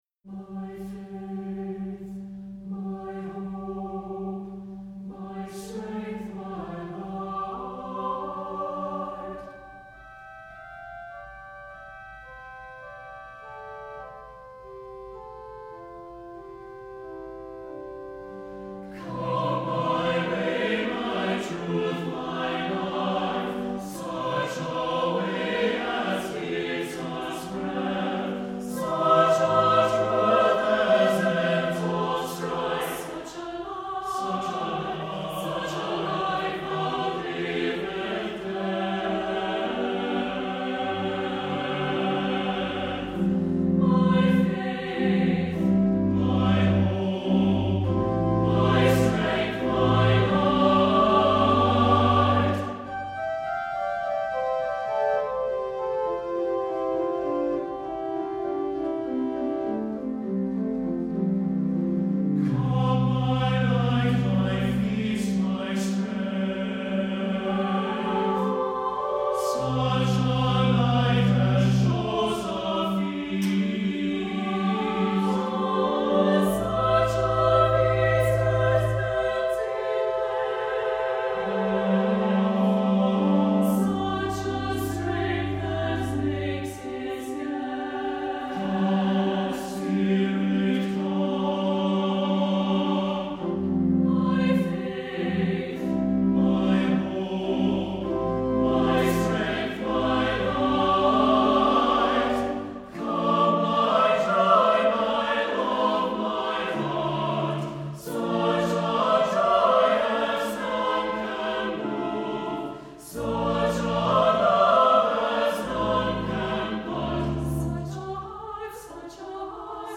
for SATB Choir and Organ (2016)